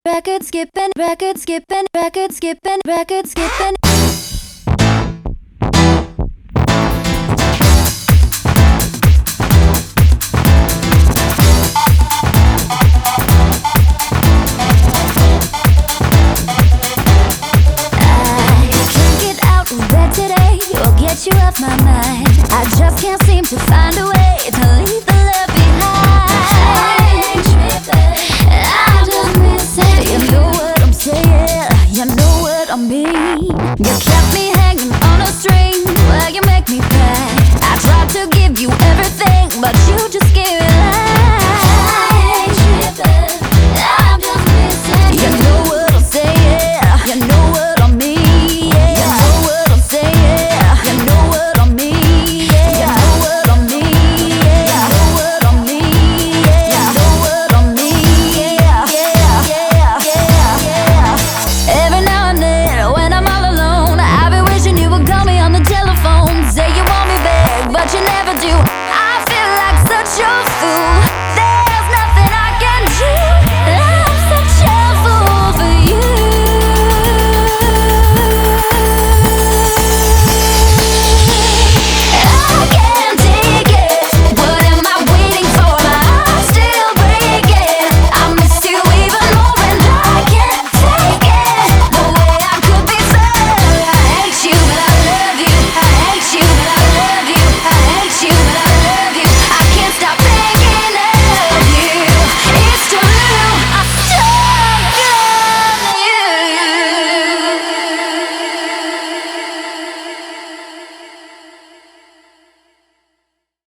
BPM100-127
Audio QualityPerfect (High Quality)
A catchy dance number